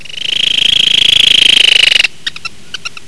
Completo (694 Kb) De advertencia
c_colilarga_advertencia.wav